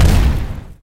mediumCannon.ogg